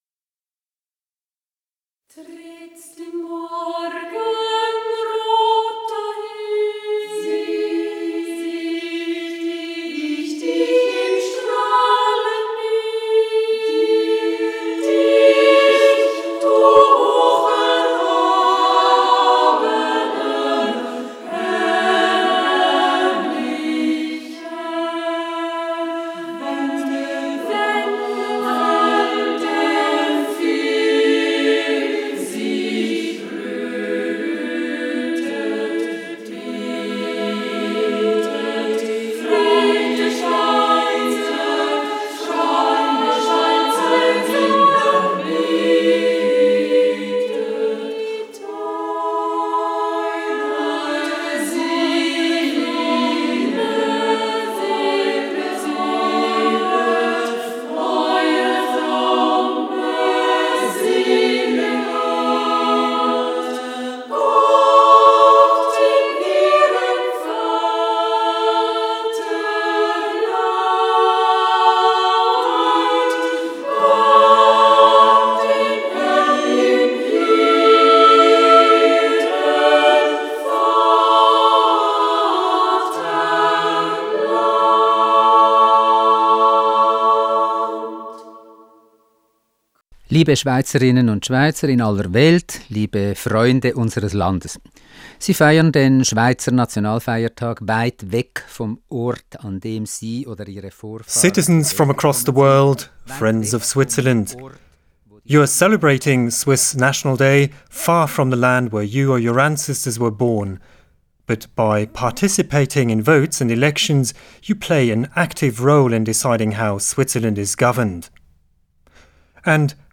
President Moritz Leuenberger's speech to the Swiss abroad on the Swiss national day.